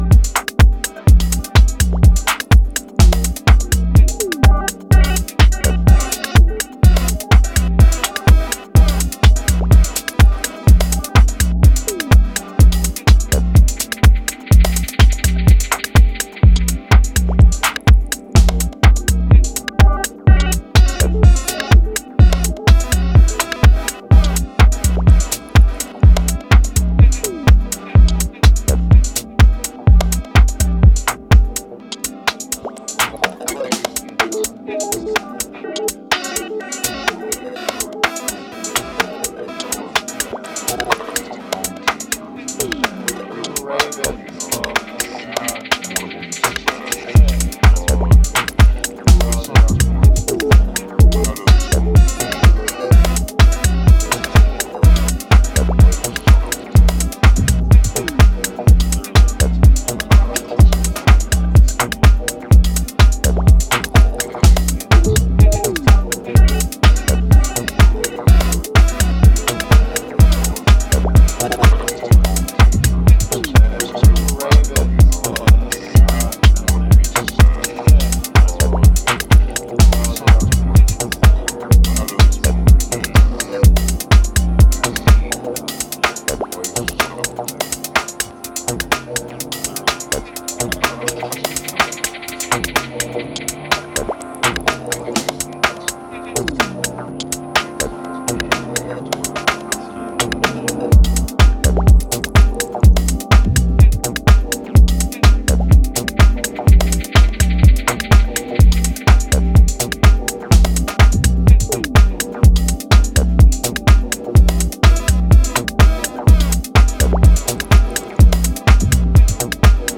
New club killer